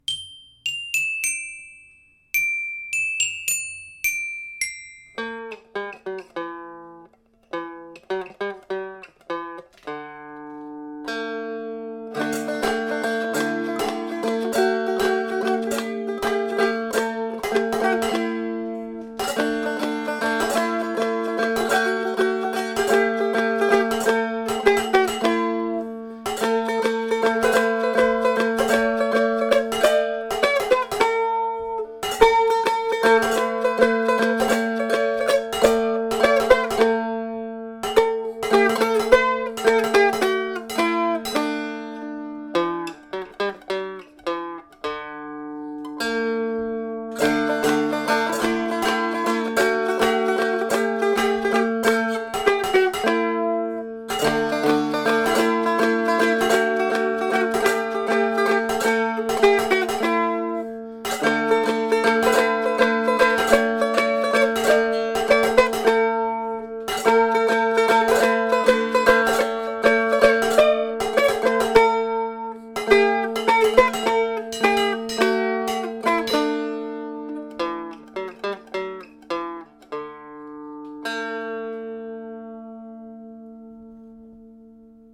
J’ai essayé de rire un peu de moi en m’amusant avec mon nouvel instrument, un dulcimer.
c3b4-ma-dulcimer-instrumentale.mp3